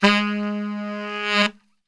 G#1 SAXSWL.wav